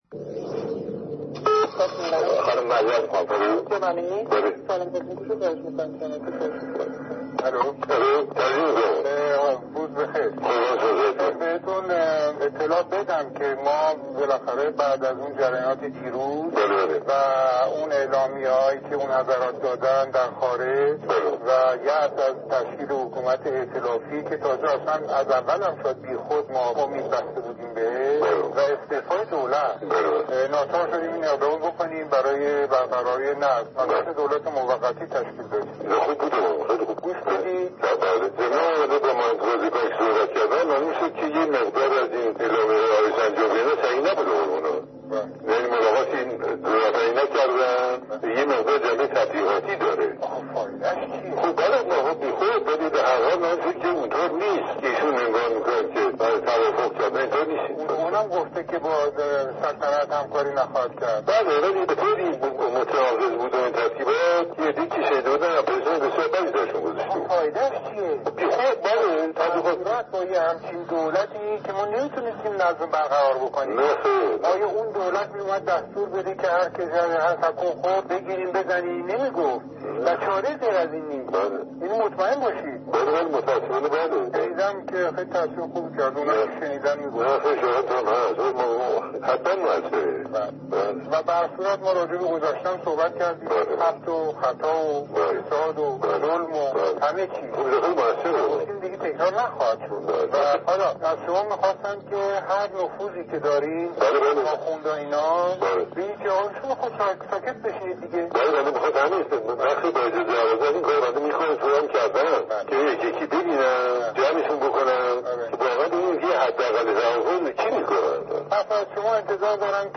محمدرضا پهلوی-گفتگوی تلفنی با علی امینی (۱۴ آبان ۱۳۵۷)، بعد از پیام تلویزیونی ۱۴ آبان ۱۳۵۷ «من نیز پیام انقلاب شما را شنیدم»